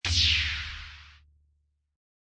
Download Lightsaber Ignition sound effect for free.
Lightsaber Ignition